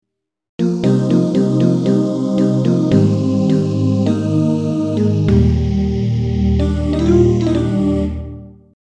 Key written in: E♭ Major
How many parts: 4
Type: Other male
All Parts mix: